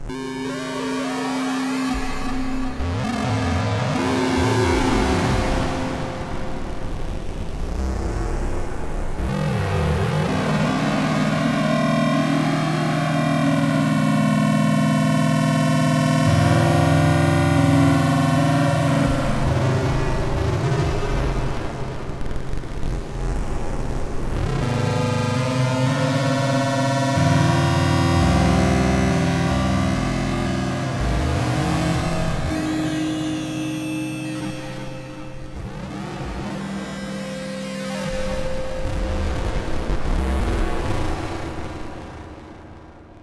then i started playing with the LoFi value and got some wild noises out of fast changes. its almost like it makes specific frequencies resonate more, not sure. sounds nuts tho! i was laughing when i discovered it because i just never expected these sounds out of this box.